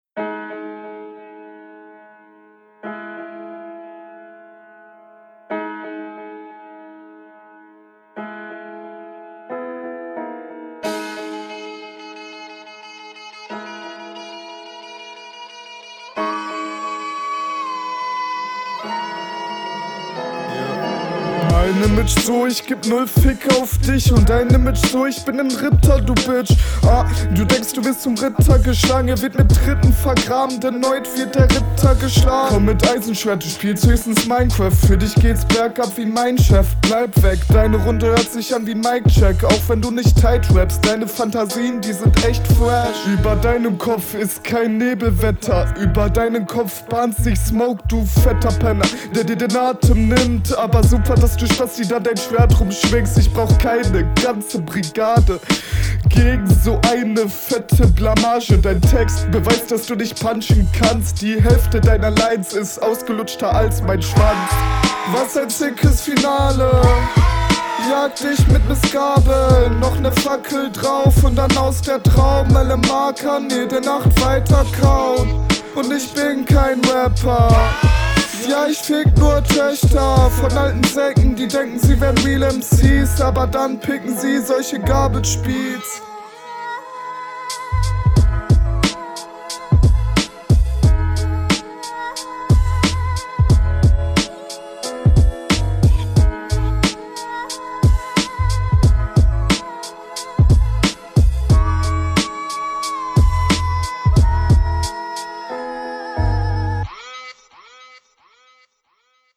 Einige Stellen sind sehr holprig und nicht richtig im Takt.
Flowtechnisch hast du hier ein paar grobe Fehler gemacht.